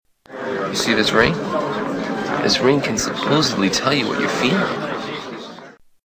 Ring
Category: Movies   Right: Personal